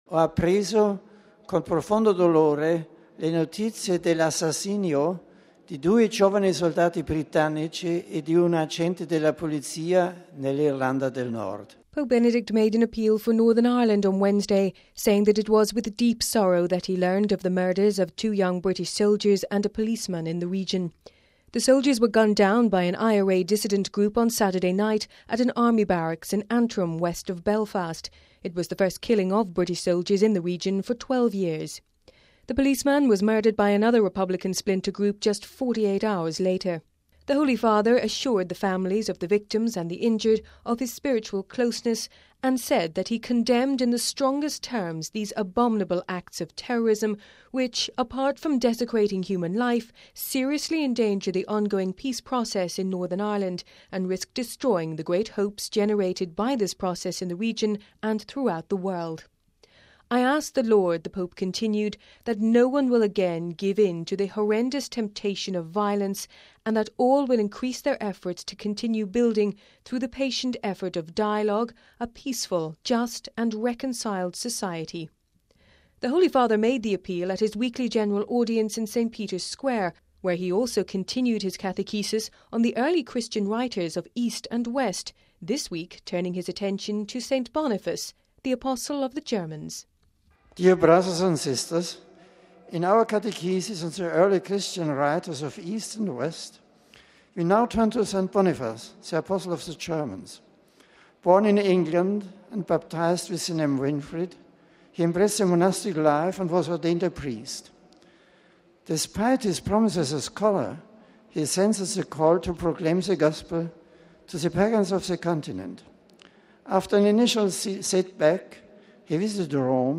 (11 Mar 09 - RV) Pope Benedict today condemned the recent murders of two British soldiers and a policeman by dissident republicans in Northern Ireland. In an appeal during his Weekly General Audience he prayed that no one would again give in to the temptation of violence.